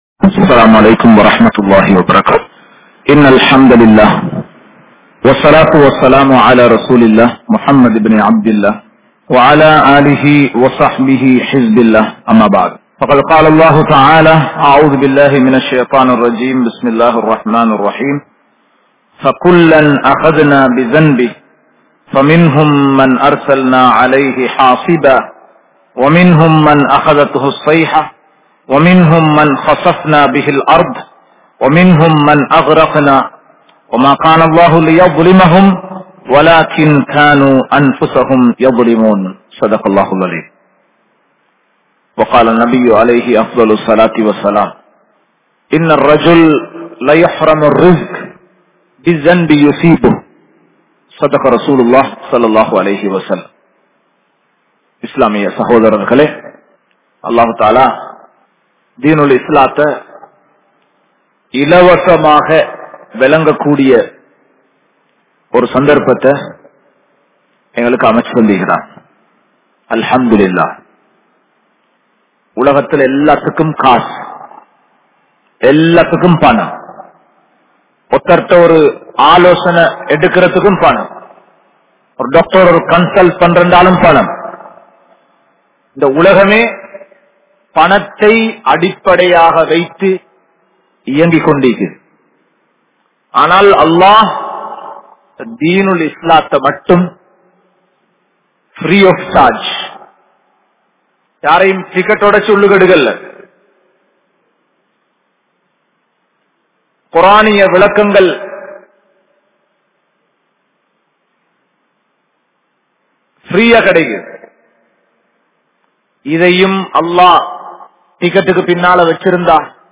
Paavangalin Vilaivuhal(பாவங்களின் விளைவுகள்) | Audio Bayans | All Ceylon Muslim Youth Community | Addalaichenai
Grand Jumua Masjith